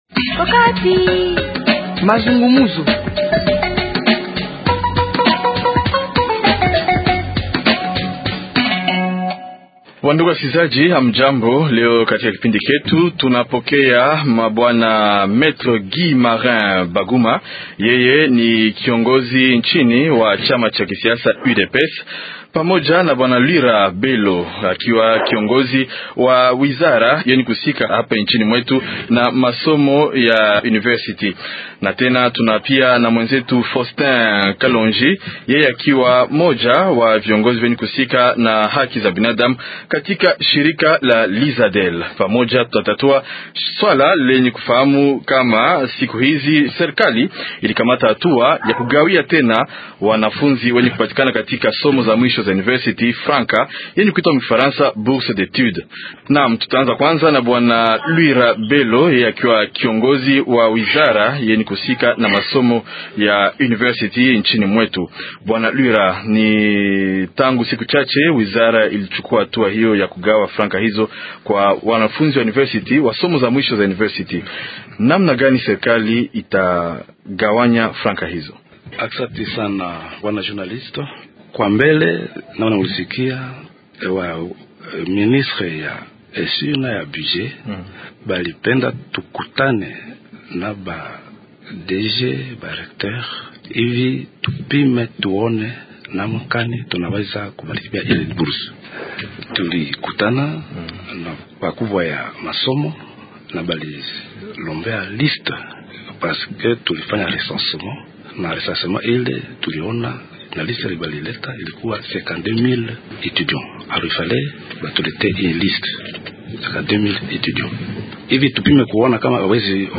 Mwaakili wa pande lenye ku ongoza inchi wakati huu pamo na wule wa pande la upimzani na wa shirika la raiya wata tatuwa slwali hilo katika kipindi hiki.